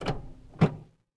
car-steer-4.ogg